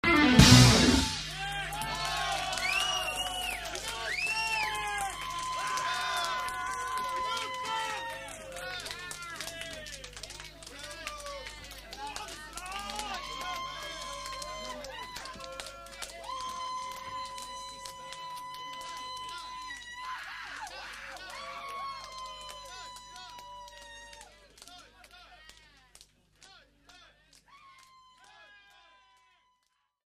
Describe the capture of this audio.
Flamman August 18, 1979 This was possible due to the Tandberg built in mixer of two mics with stereo line input.